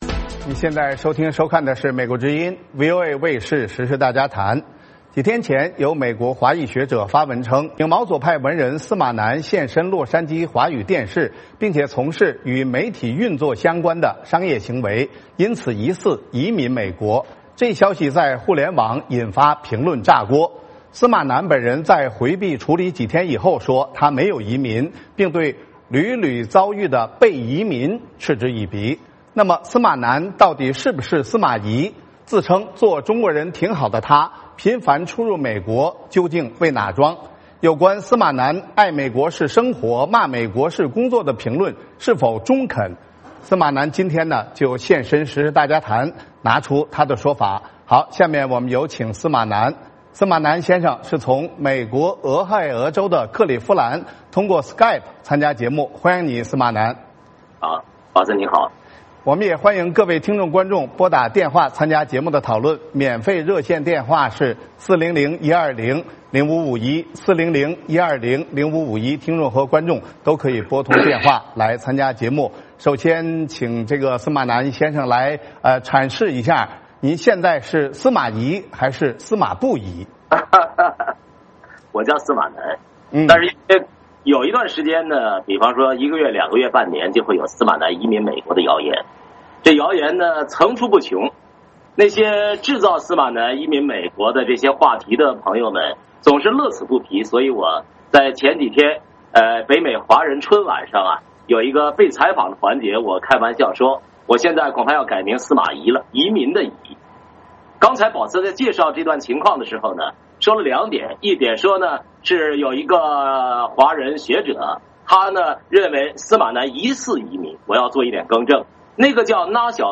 司马南今天现身时事大家谈，拿出他的说法。